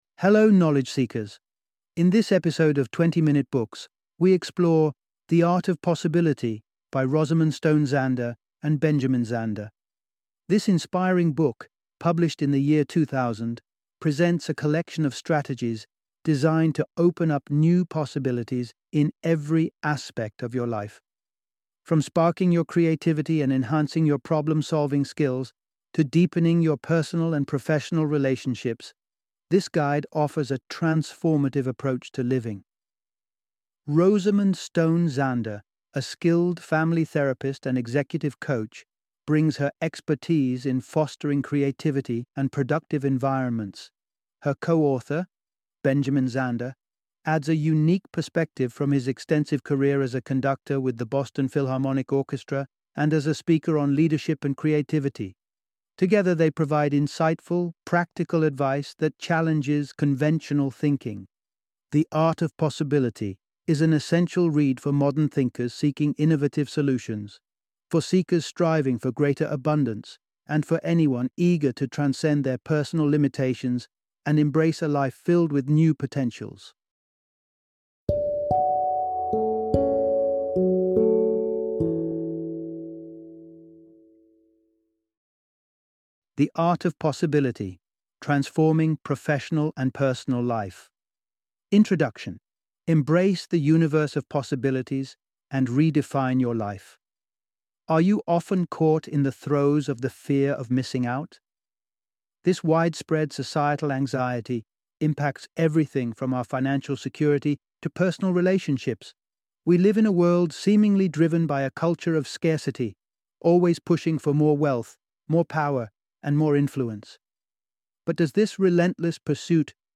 The Art of Possibility - Audiobook Summary